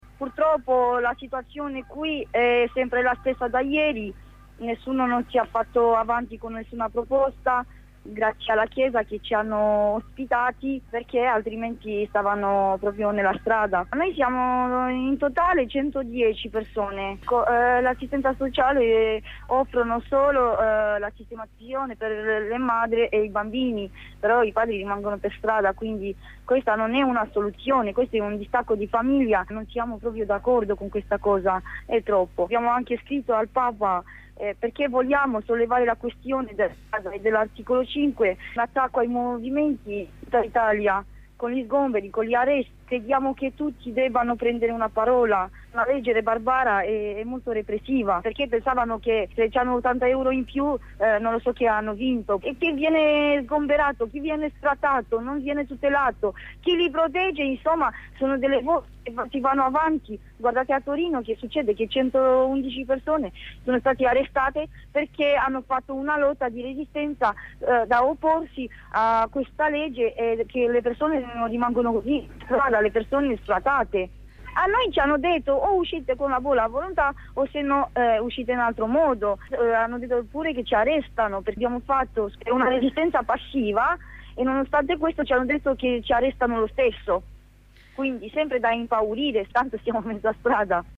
La corrispondenza con una delle occupanti